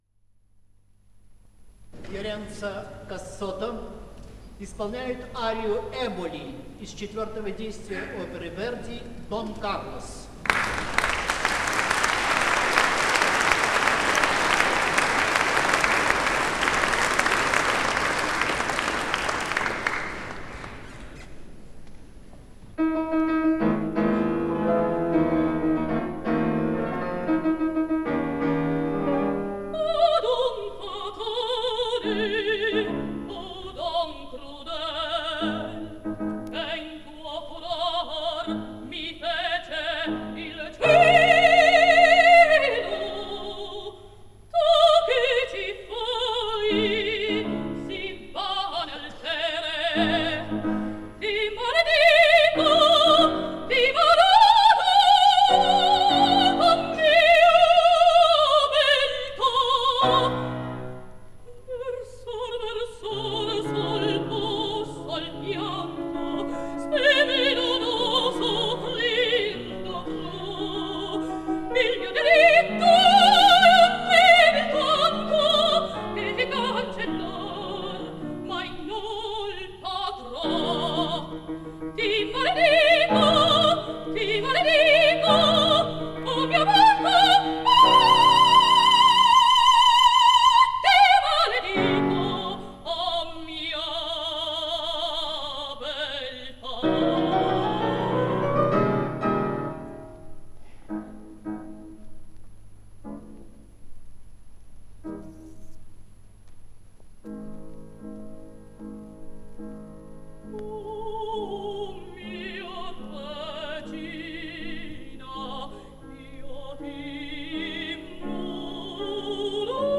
Записано в дни гастролей театра Ла Скала в Москве